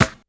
Snares
snr_25.wav